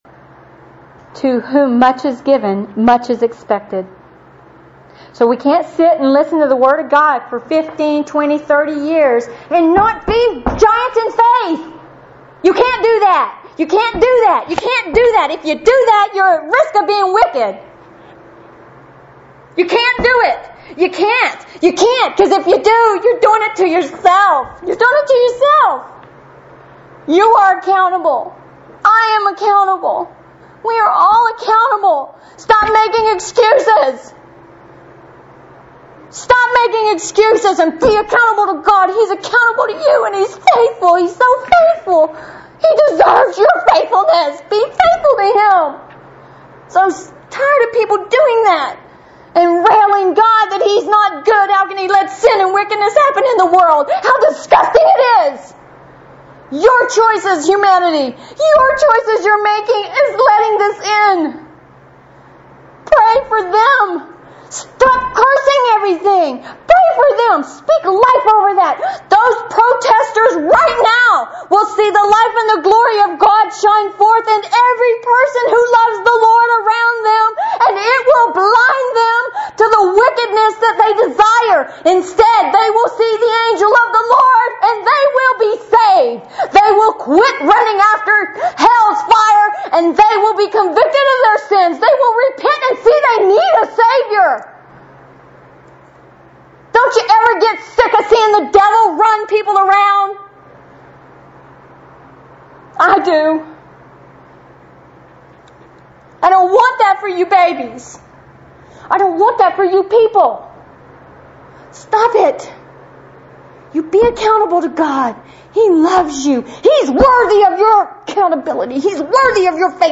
A fiery 3-minute excerpt from Sunday